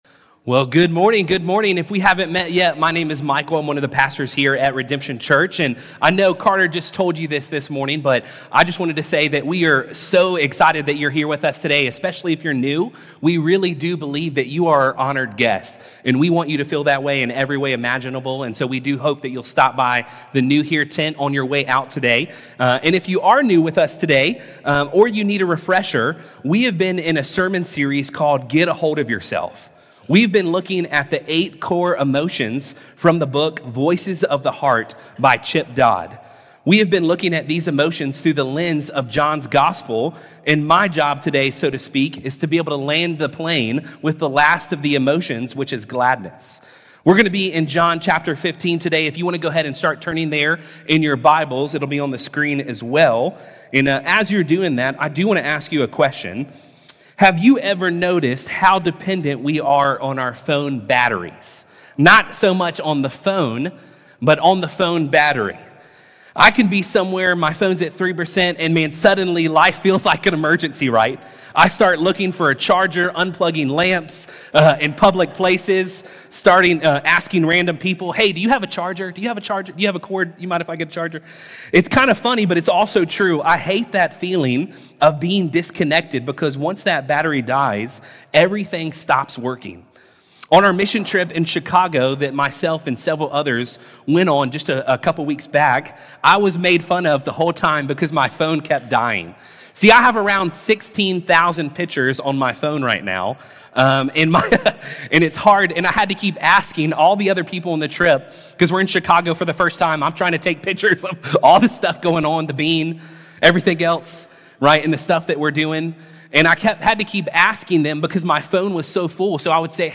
This sermon is an invitation to stop chasing counterfeit happiness and start abiding in the love that never runs out.